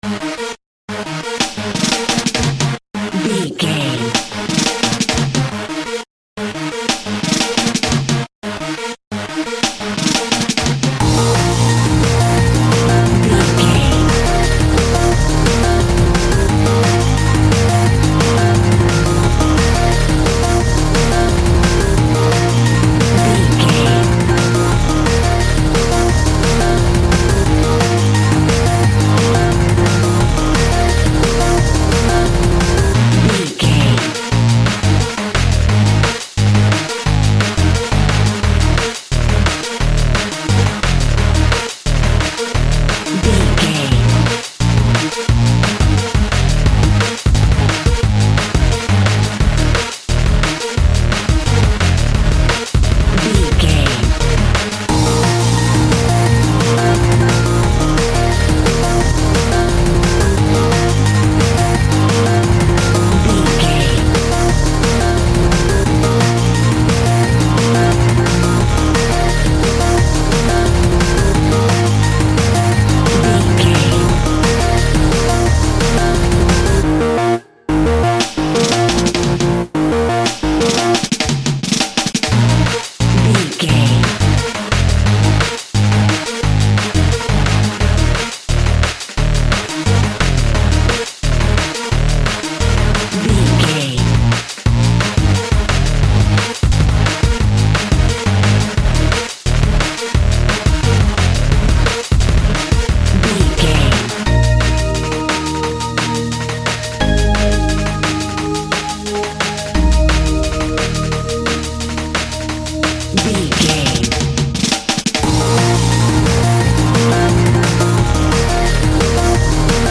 Club Sounds Drum and Bass.
Ionian/Major
A♭
Fast
futuristic
hypnotic
industrial
frantic
aggressive
dark
drum machine
synthesiser
electronic
sub bass
Neurofunk
synth leads
synth bass